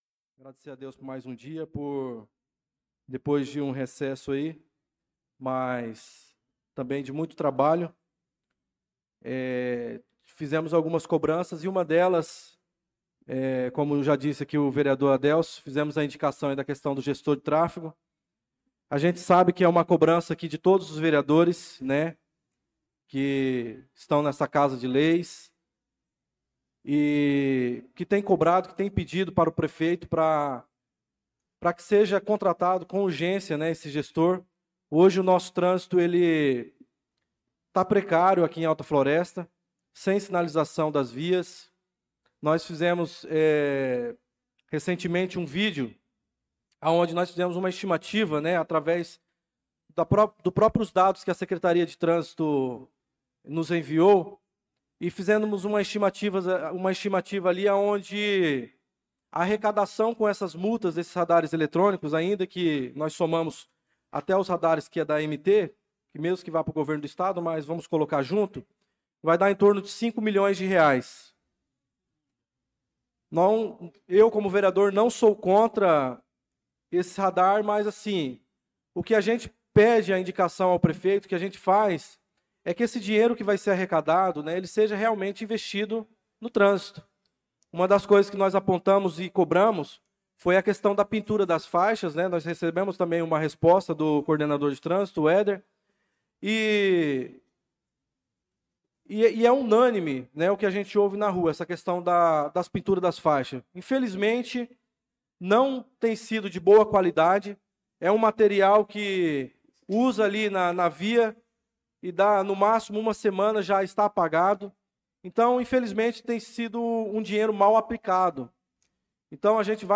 Pronunciamento do vereador Darlan Carvalho na Sessão Ordinária do dia 04/08/2025.